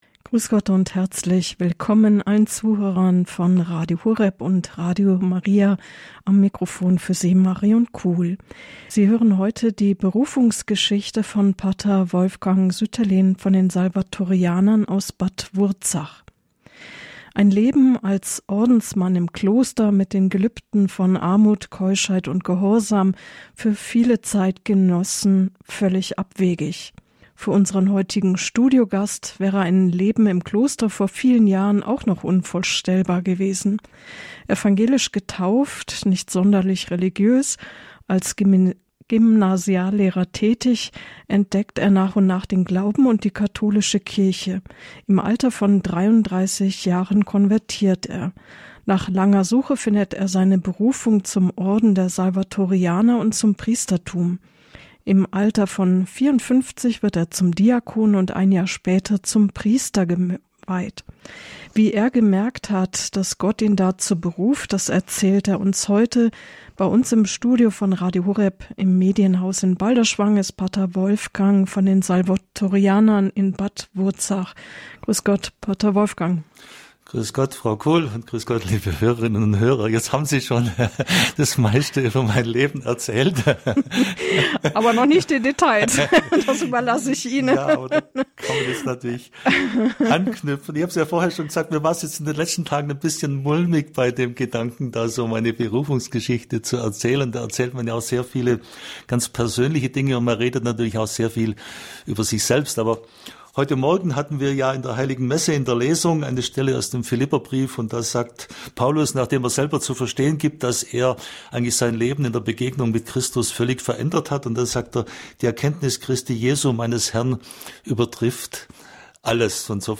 Berufen – eine Radiosendung